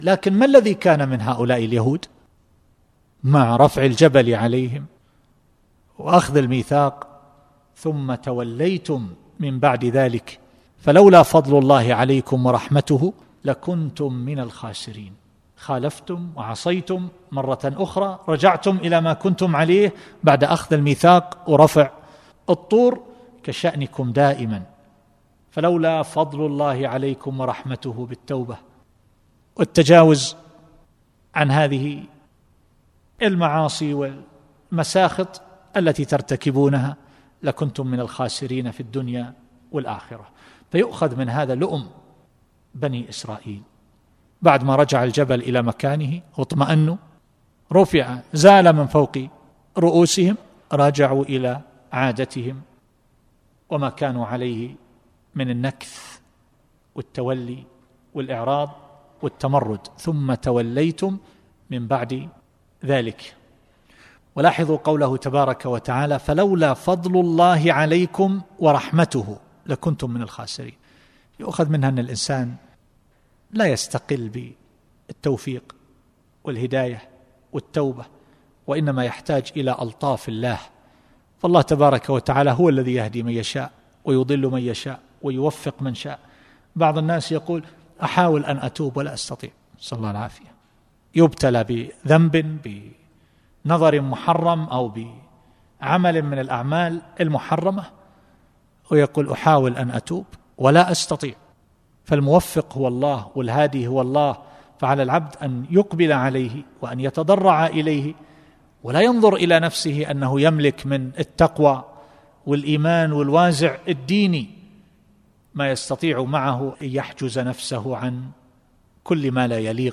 التفسير الصوتي [البقرة / 63]